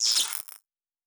Spark 04.wav